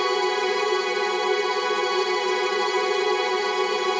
GS_TremString-B6+9.wav